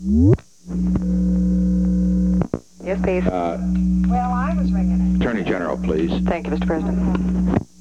Secret White House Tapes
Conversation No. 6-35
Location: White House Telephone
The President talked with the White House operator.